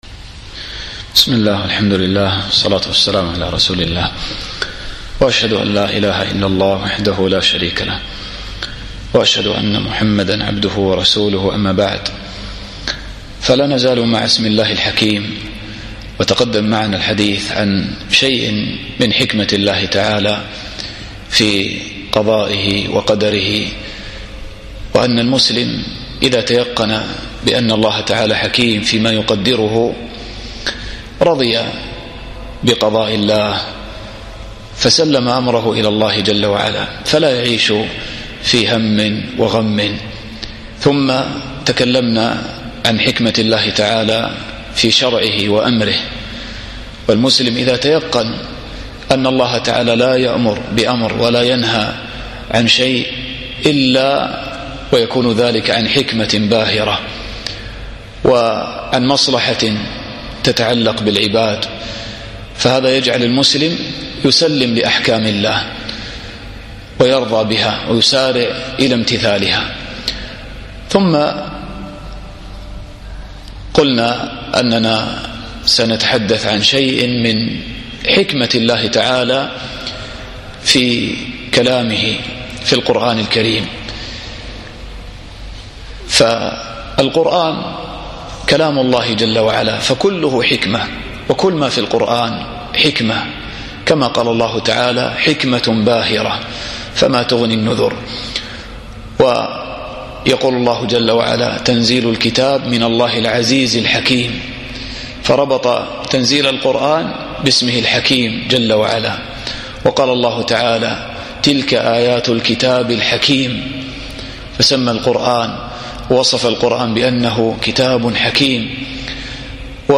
الدرس التاسع عشر